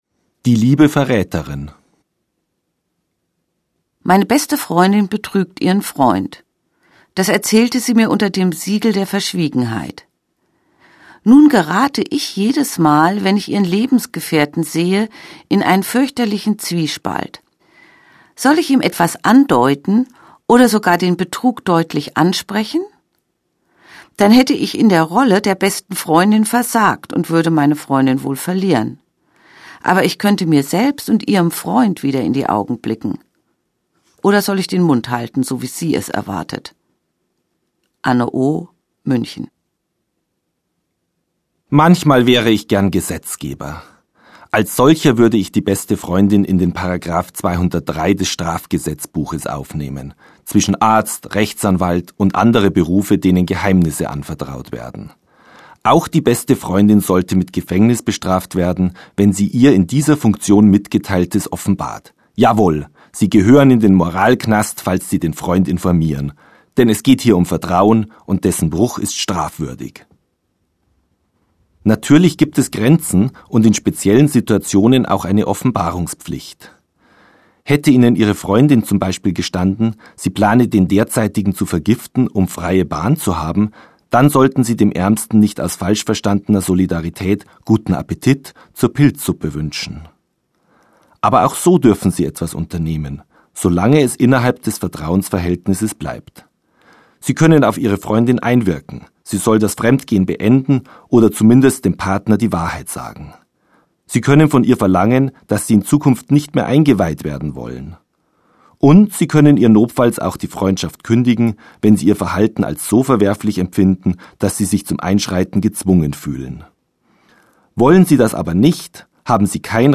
Dr. Dr. Rainer Erlinger beantwortet Fragen der Alltagsmoral